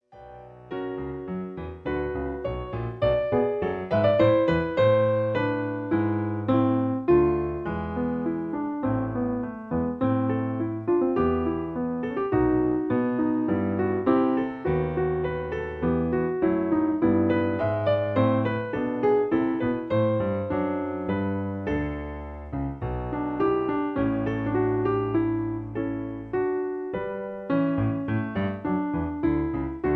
In E. Piano Accompaniment